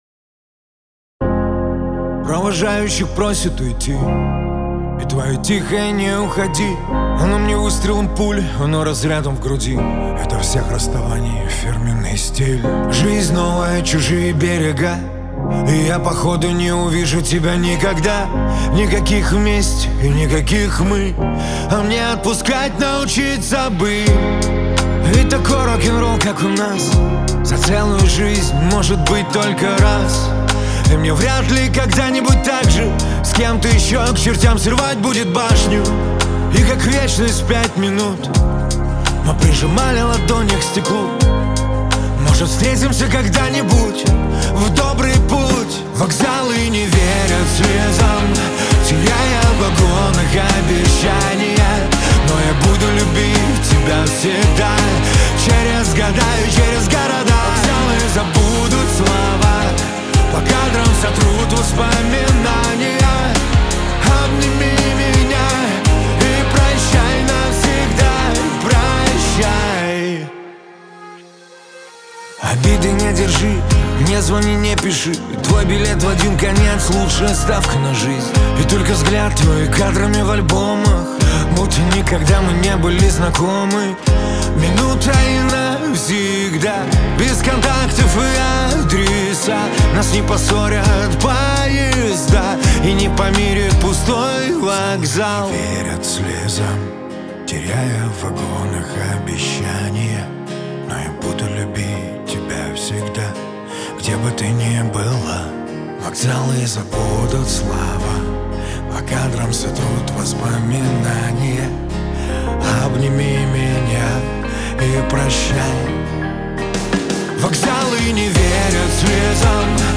Шансон , грусть